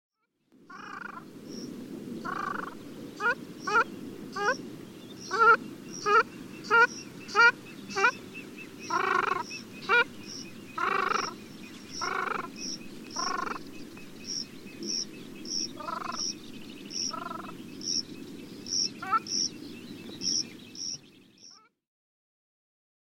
tadorne-casarca.mp3